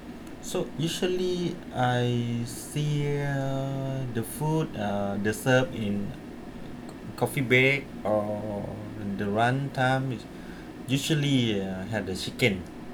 S1 = Brunei female S2 = Laos male Context: S2 is talking about the food he likes to eat, particularly that he likes meat.
Intended Words: coffee-break , lunchtime Heard as: coffee bake , runtime
The [r] is omitted in break ; there is an [r] at the start of lunchtime ; the [tʃ] is missing from the end of lunch .